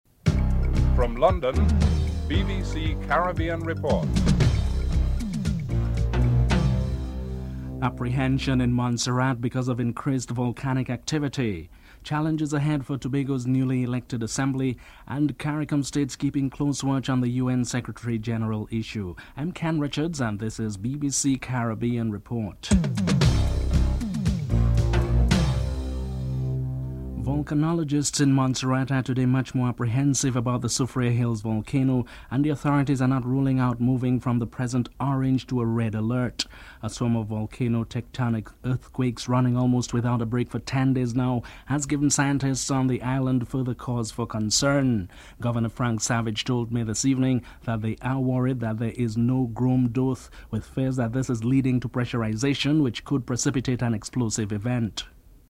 1. Headlines (00:00-00:26)
2. Apprehension in Montserrat because of creased volcanic activity. Governor Frank Savage is interviewed (00:27-03:33)